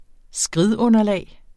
Udtale [ ˈsgʁið- ]